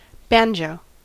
Ääntäminen
Ääntäminen US : IPA : /ˈbæn.dʒoʊ/